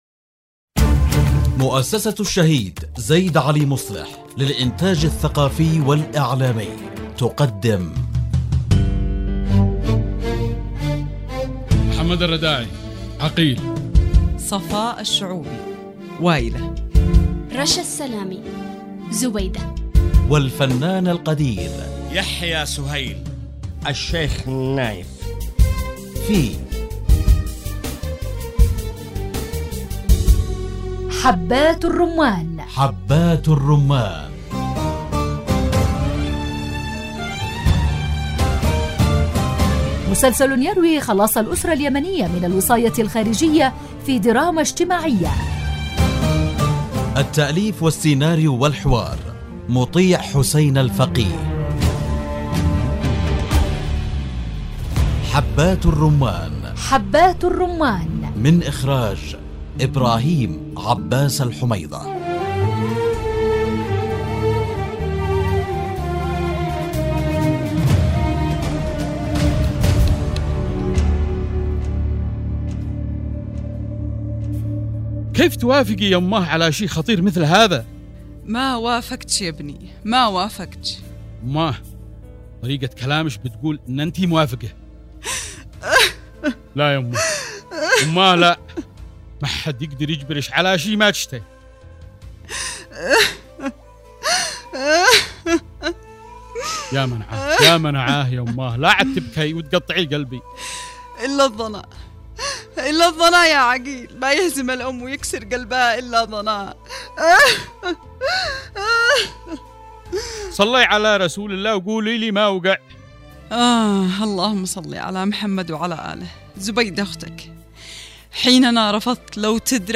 الدراما